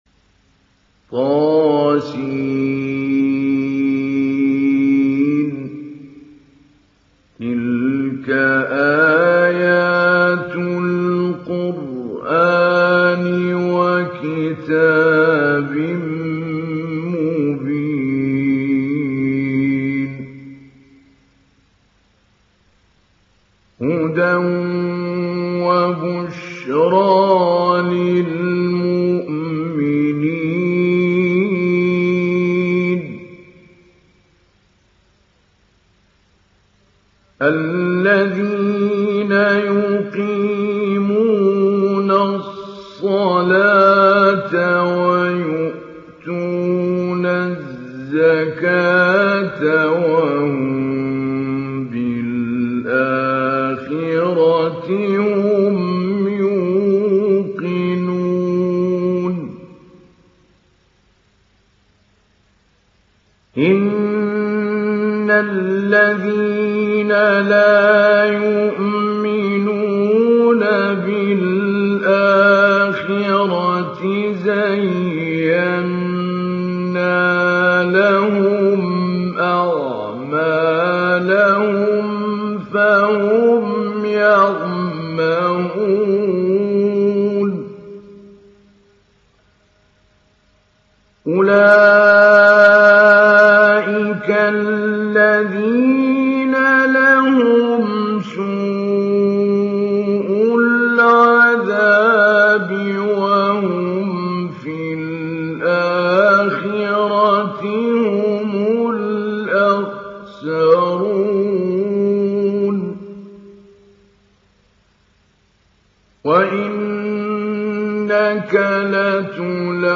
Sourate An Naml Télécharger mp3 Mahmoud Ali Albanna Mujawwad Riwayat Hafs an Assim, Téléchargez le Coran et écoutez les liens directs complets mp3
Télécharger Sourate An Naml Mahmoud Ali Albanna Mujawwad